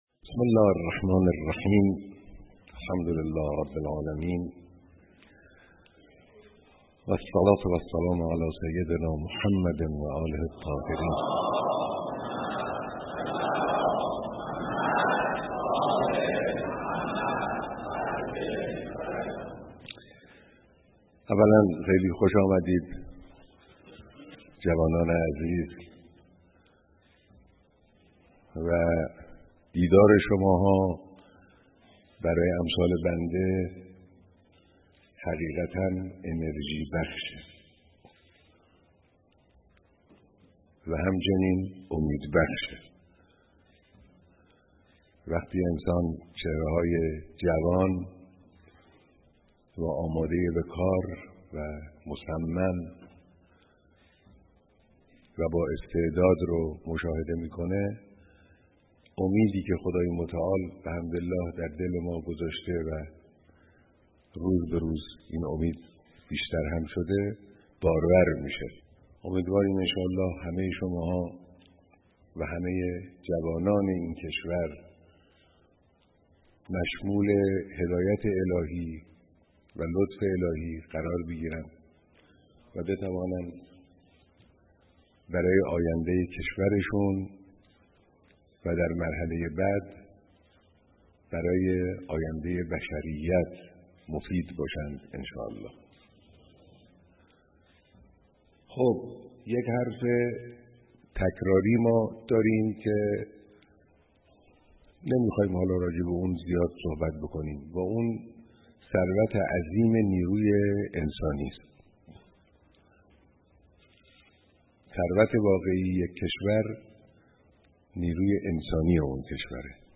شرکت‌کنندگان در نهمین همایش ملی «نخبگان فردا» تا ساعتی دیگر با حضور در حسینیه‌ی امام خمینی با رهبر انقلاب دیدار می‌کنند.